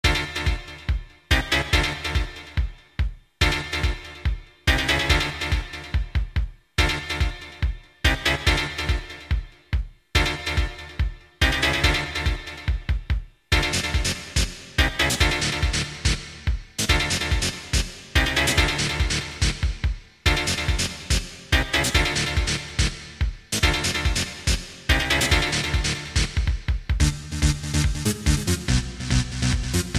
Digital Stereo Techno-Rave Cyber-Delic Audio Sound Tracks